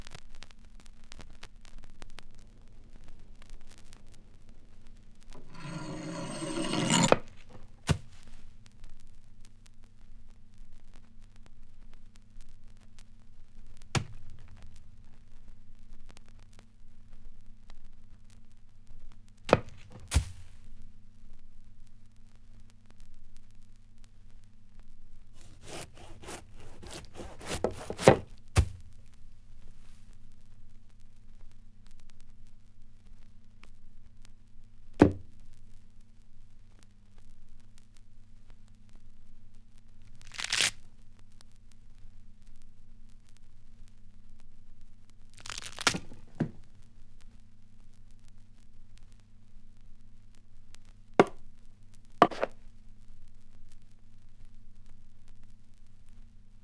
• the guillotine.wav
the_guillotine_vUl.wav